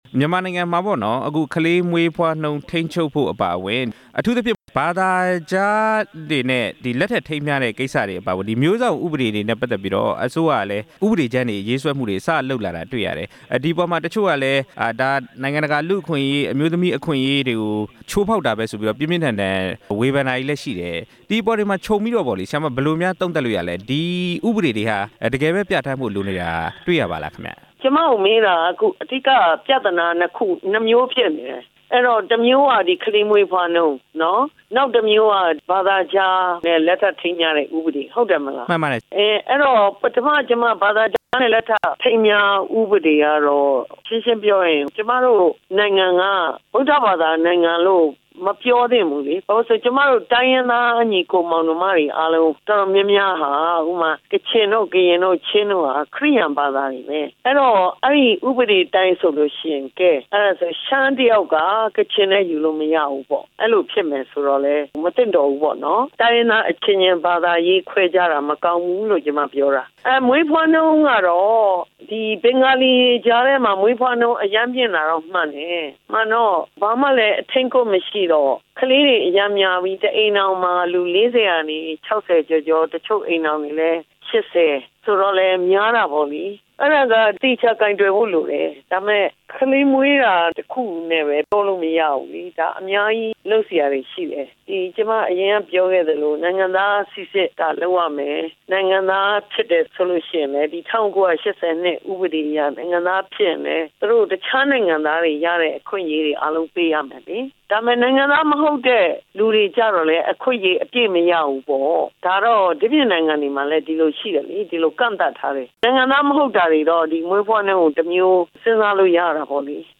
အထူးအကြံပေး ဒေါက်တာ ဒေါ်ရင်ရင်နွယ်နဲ့ ဆက်သွယ်မေးမြန်းချက်